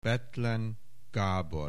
Aussprache Aussprache